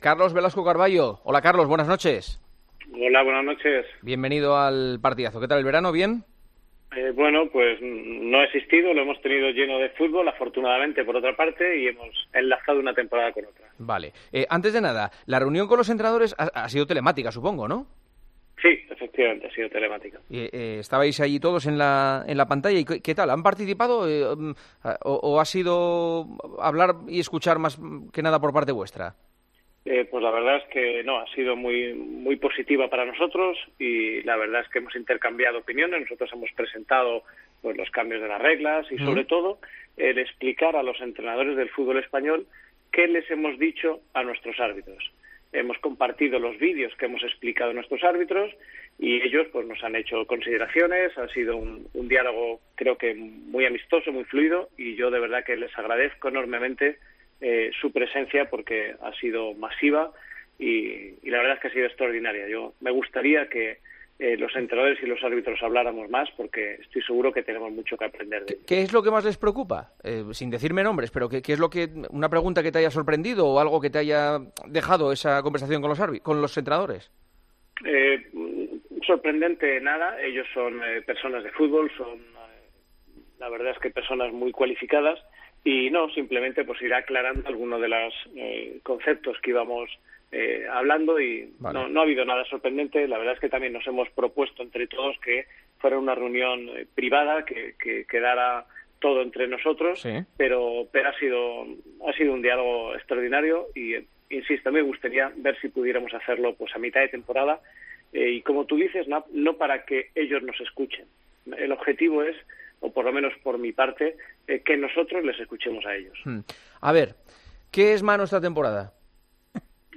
En su visita a El Partidazo de COPE, Velasco Carballo ha querido destacar que la reunión de este jueves por la mañana con los entrenadores ha sido “positiva” y que le gustaría “repetir”.